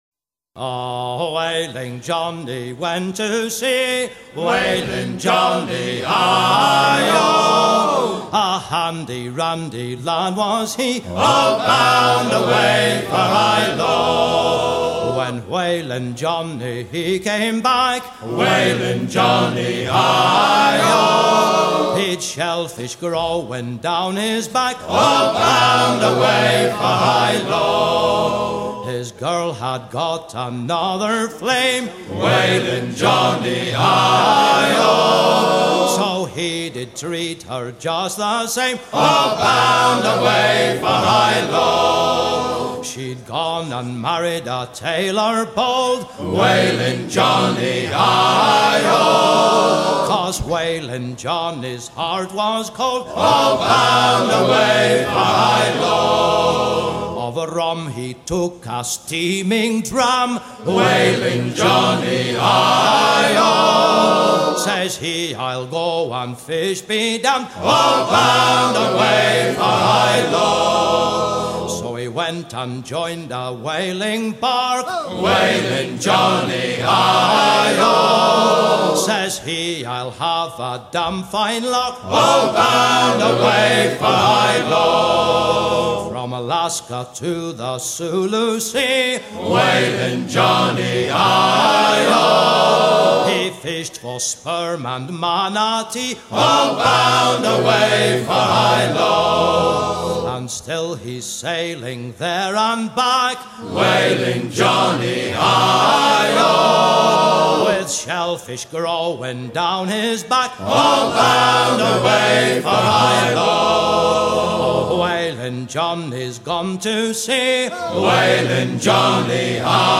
chantey de baleiniers
Genre laisse